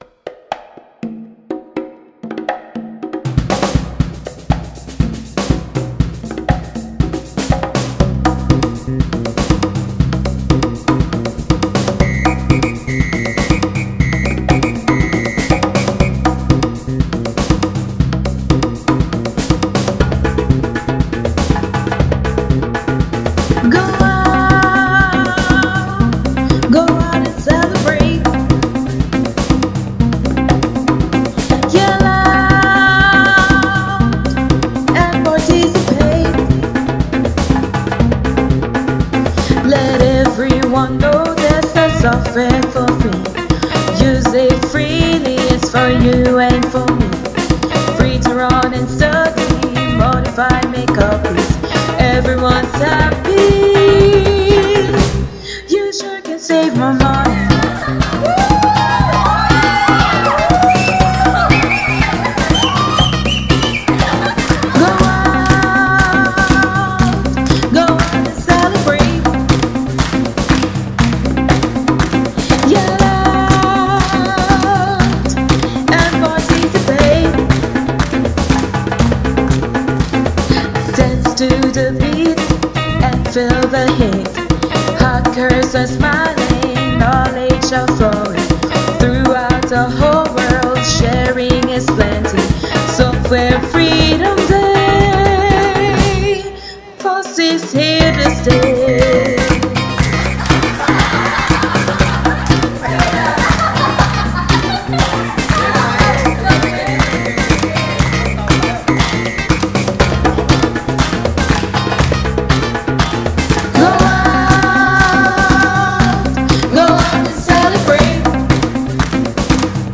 Dear Guys: Our team created a theme song for our Software Freedom Day event this year!